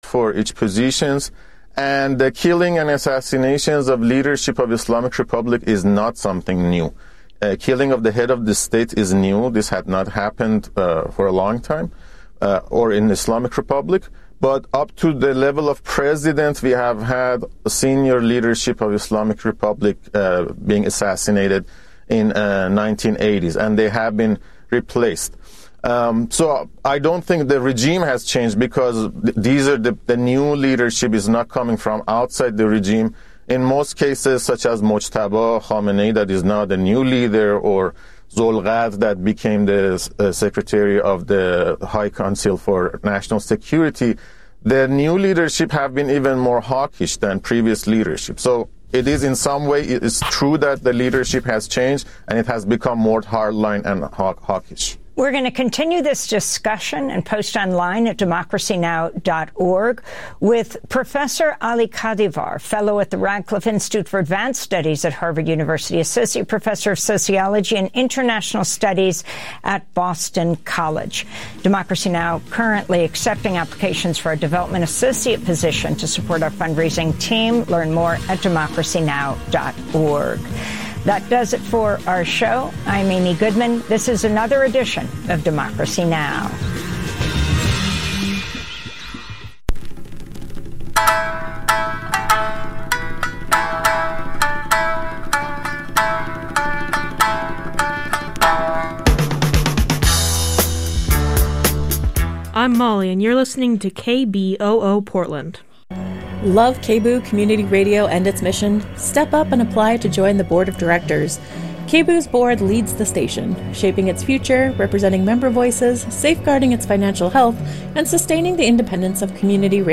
Evening News on 03/30/26